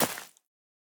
Minecraft Version Minecraft Version snapshot Latest Release | Latest Snapshot snapshot / assets / minecraft / sounds / block / azalea / break2.ogg Compare With Compare With Latest Release | Latest Snapshot
break2.ogg